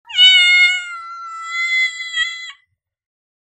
かわいい子ネコの鳴き声。
子ネコの鳴き声 着信音
試聴とダウンロード 小さな子ネコが「にゃー」と鳴くかわいらしい音声を収録したものです。